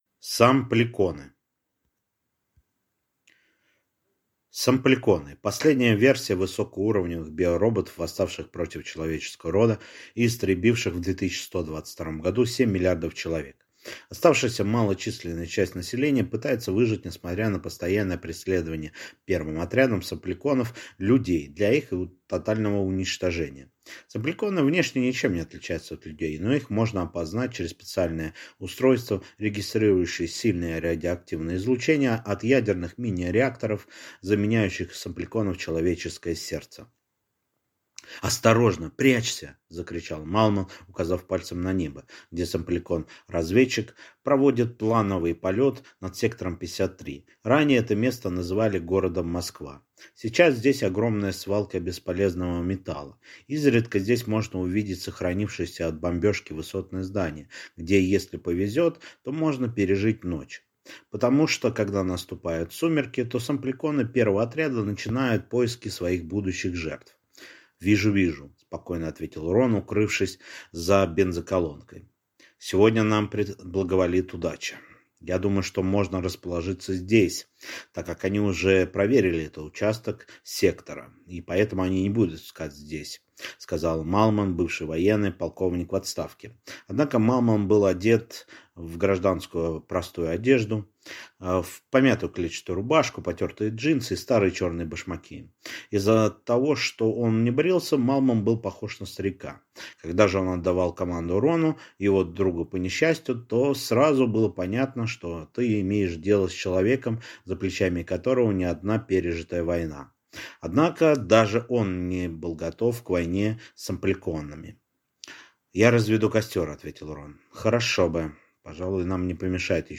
Аудиокнига Сампликоны | Библиотека аудиокниг